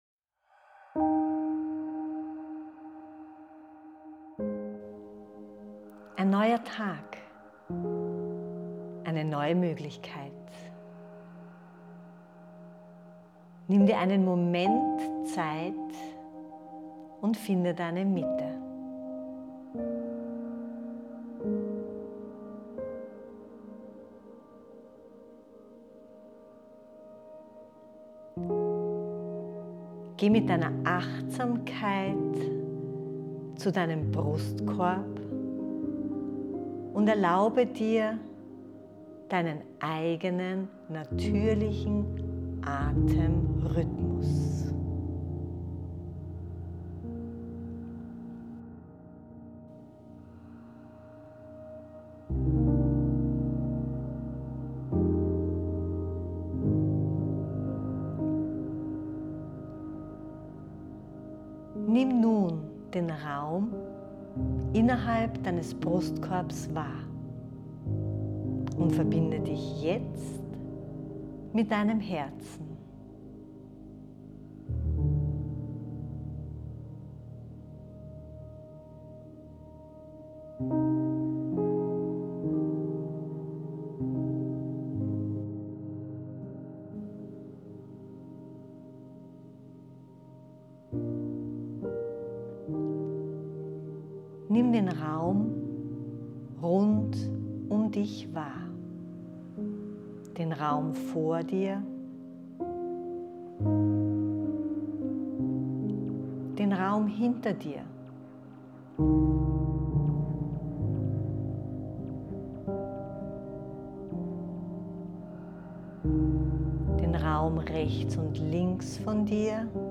Die geführte Meditation verbindet achtsame Übungen mit kraftvollen Affirmationen, um dich mental und emotional optimal auf deinen Tag vorzubereiten.